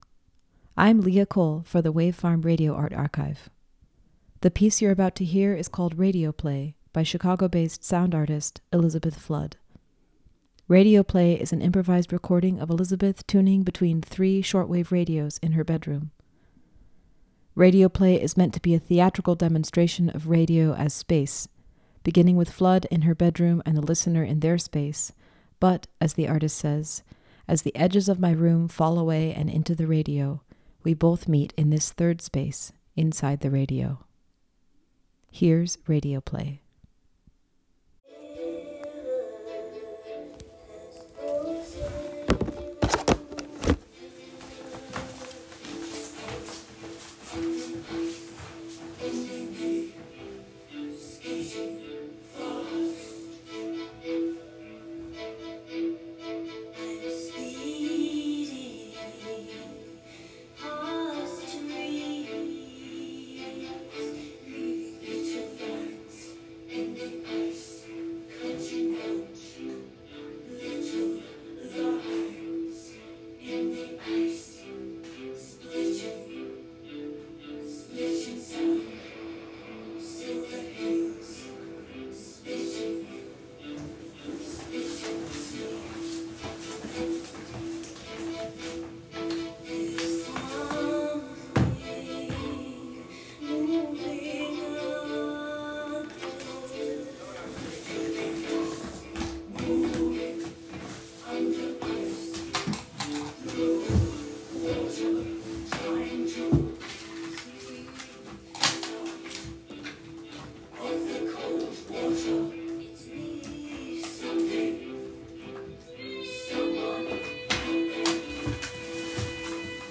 improvised recording